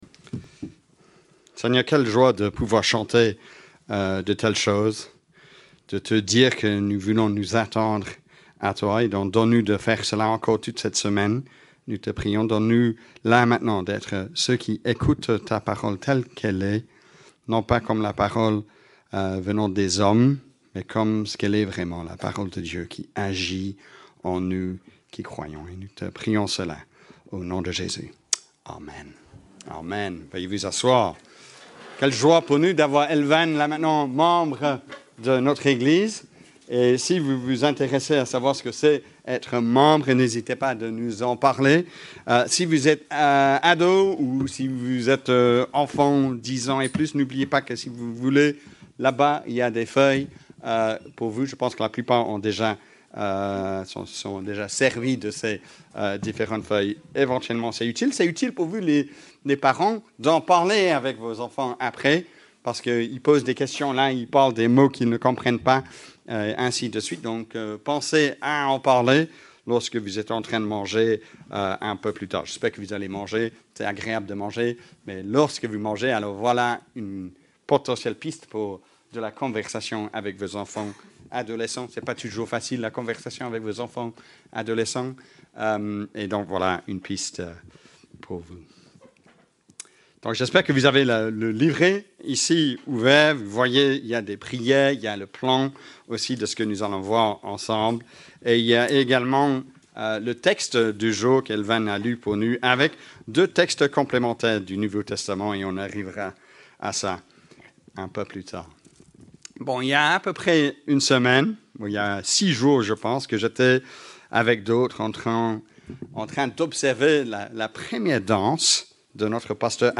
Prédication_October-30th_Audio.mp3